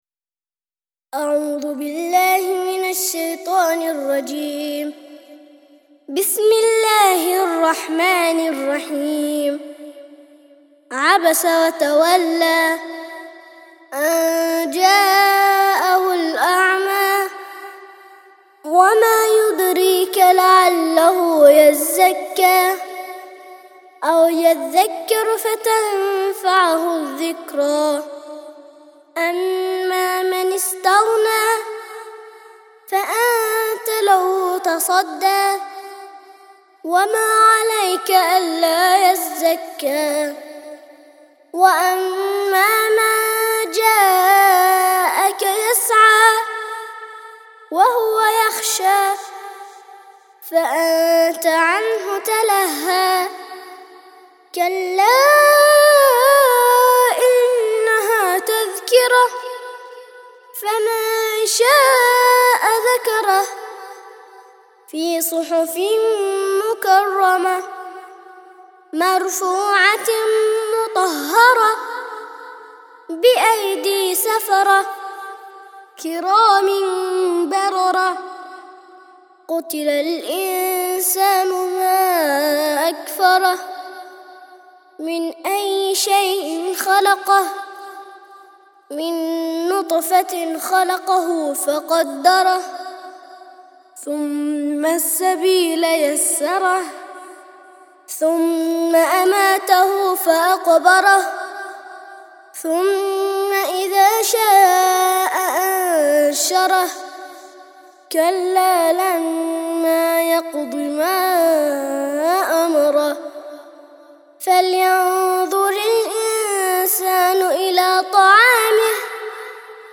80- سورة عبس - ترتيل سورة عبس للأطفال لحفظ الملف في مجلد خاص اضغط بالزر الأيمن هنا ثم اختر (حفظ الهدف باسم - Save Target As) واختر المكان المناسب